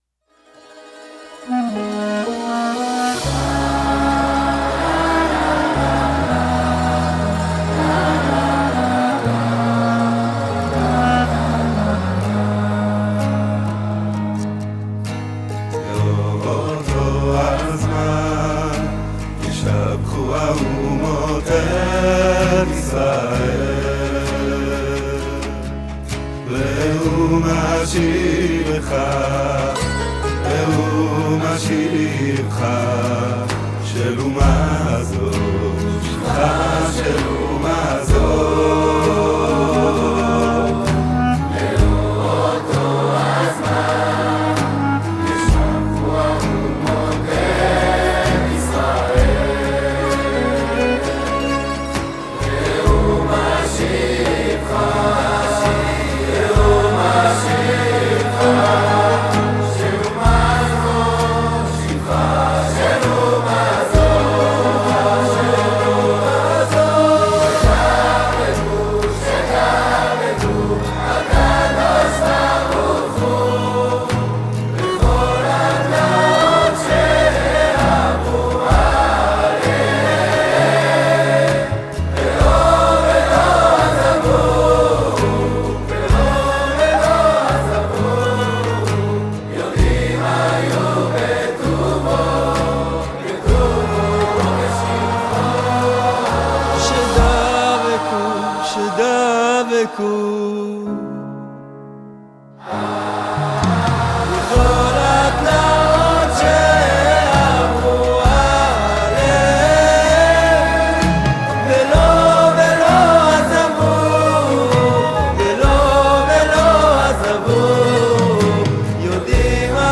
שירת המונים